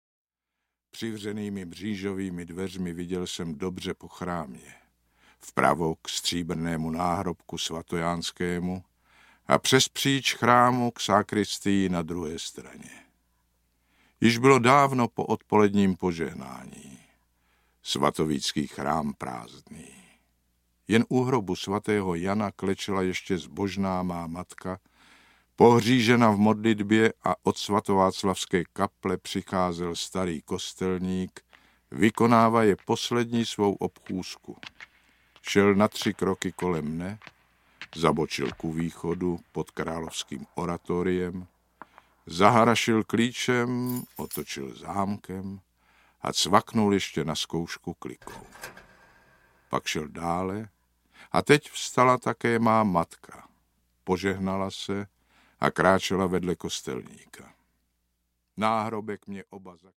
Svatováclavská mše audiokniha
Z Povídek malostranských s mistrem slova Janem Kanyzou.
Ukázka z knihy